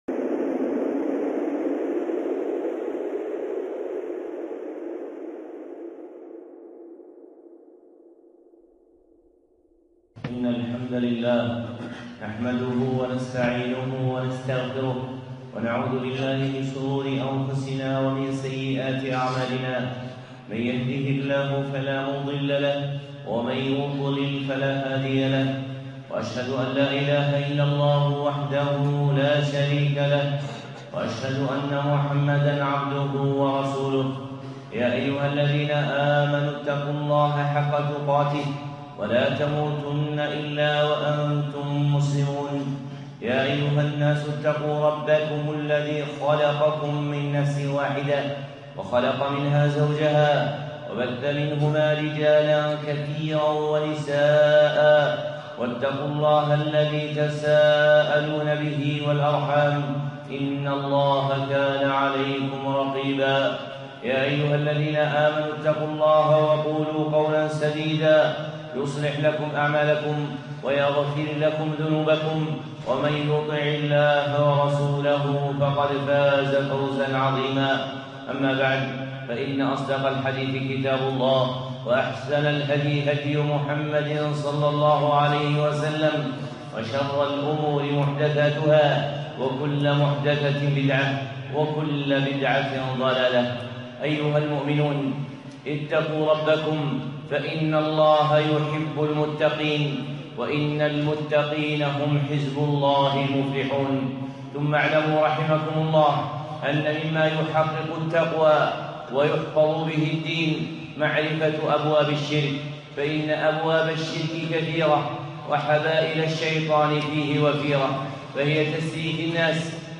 خطبة (مبطلة التمائم)